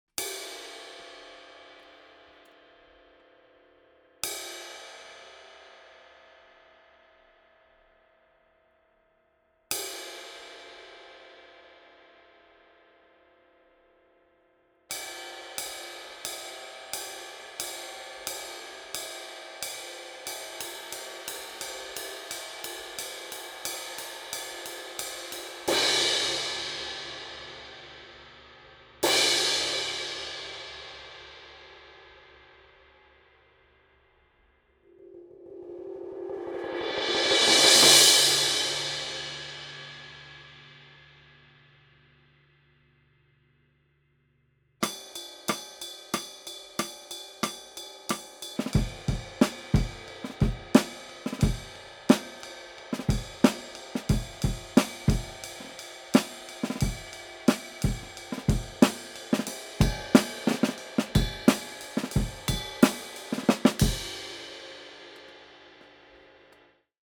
Hand-hammered from B20 bronze.
Cymbals